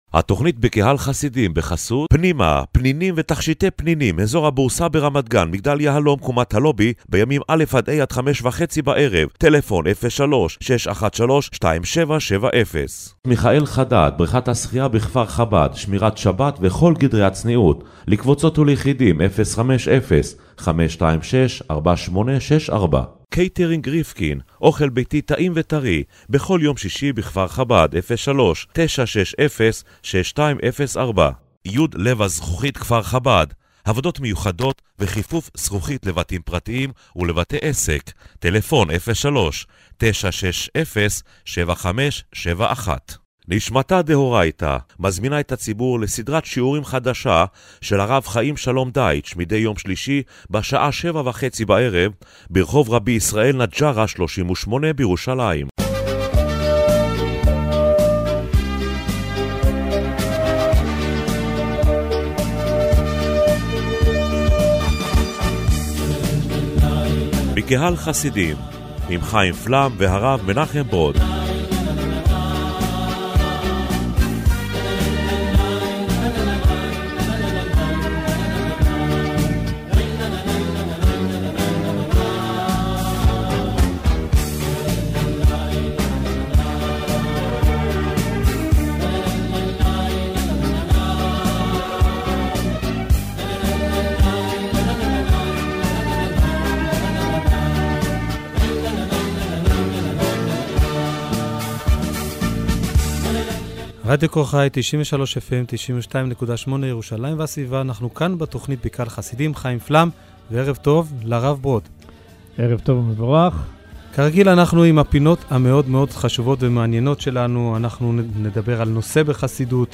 במרכז תכנית הרדיו השבועית 'בקהל חסידים' השבוע עמדה התביעה החסידות לעסוק ב'אתכפיא', ונדונה השאלה אם פירוש הדבר שהחסידות תומכת ב'נדרים' ואיסורים שהאדם מטיל על עצמו. בפינה 'הסיפור החסידי ומה שמאחוריו' הובא סיפור על העשיר שפקפק בדברי ה'חוזה' שהקב"ה יכול להוריש ולהעשיר ברגע אחד.